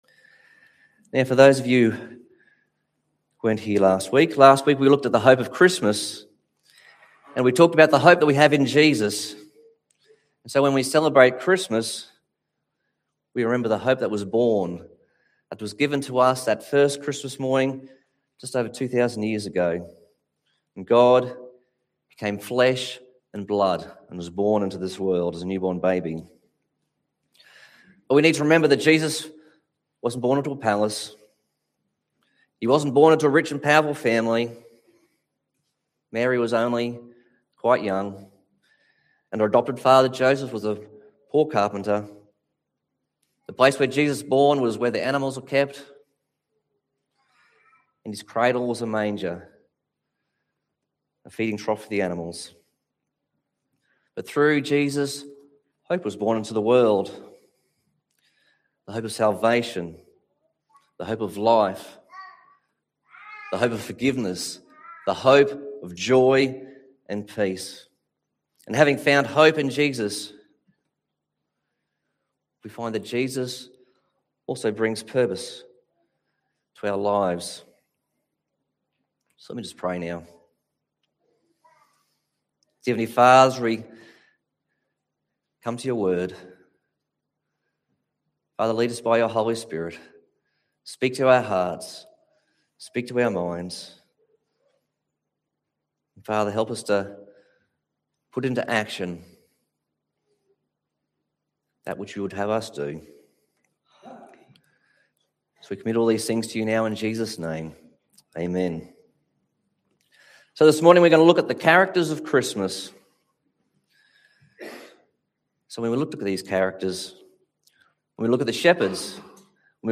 Passage: Luke 2:8-18, Matthew 2:1-12, Luke 2:21-35 Service Type: Sunday Morning